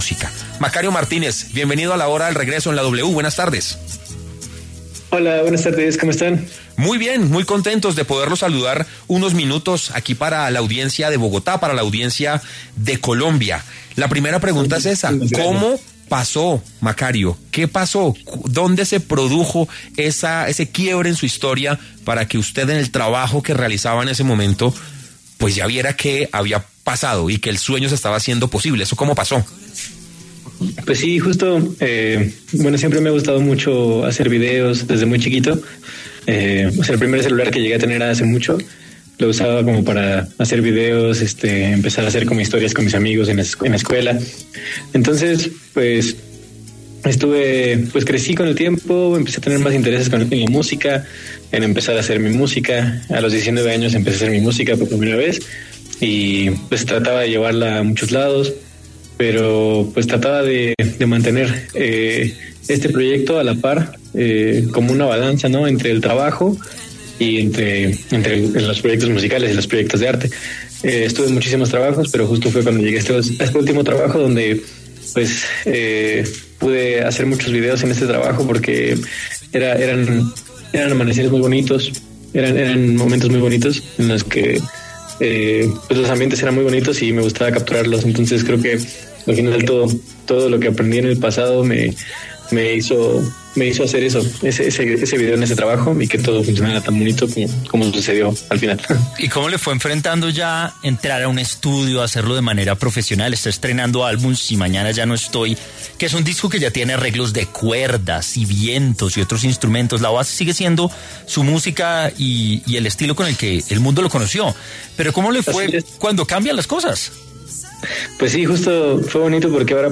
Macario Martínez, músico y artista mexicano, pasó por los micrófonos de La Hora del Regreso de W Radio, la historia detrás de su ascenso viral, su proceso creativo y su carrera musical, presentando su álbum más reciente.